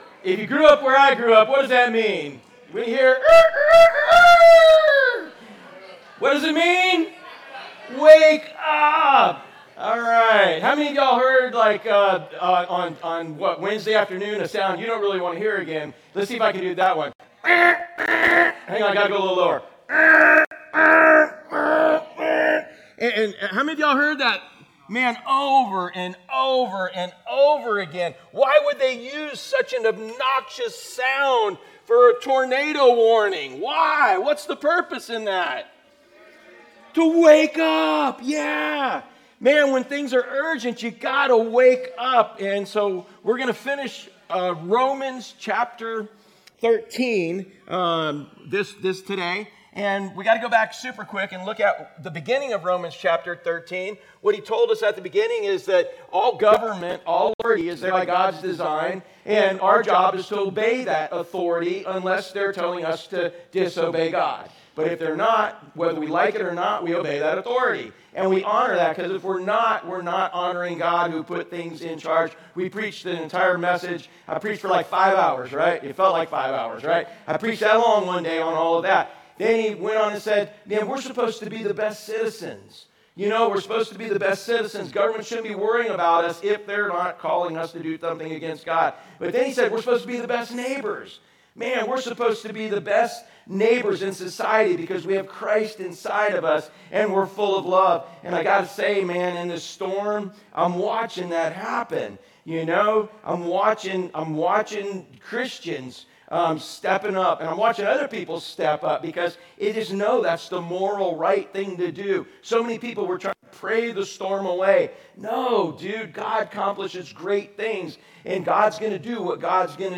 Sermons | Driftwood Church at the Beach